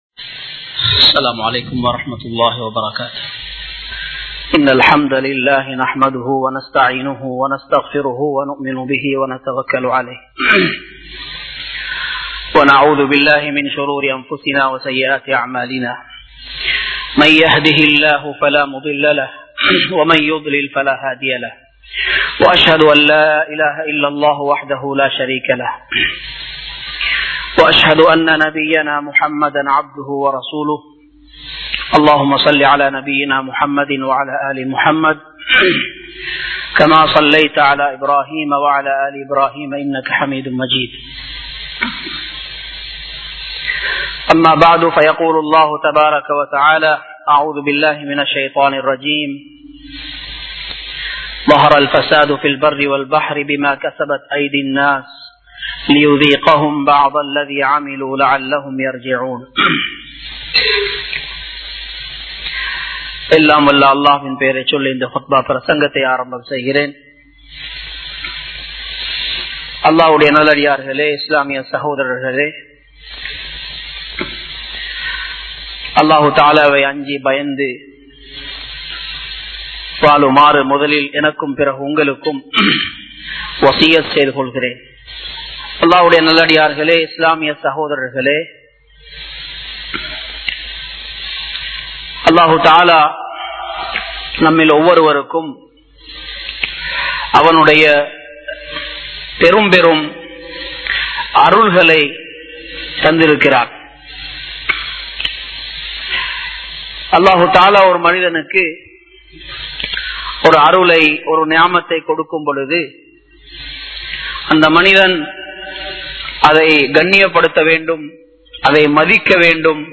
Islaamiya Paarvayil Selvam (இஸ்லாமிய பார்வையில் செல்வம்) | Audio Bayans | All Ceylon Muslim Youth Community | Addalaichenai
Dehiwela, Muhideen (Markaz) Jumua Masjith